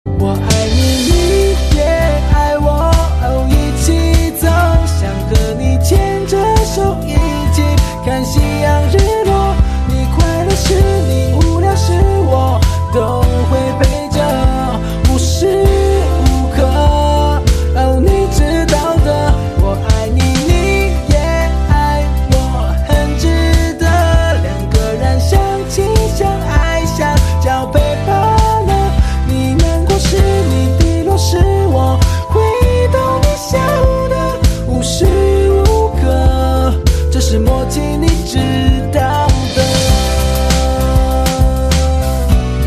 M4R铃声, MP3铃声, 华语歌曲 30 首发日期：2018-05-14 13:41 星期一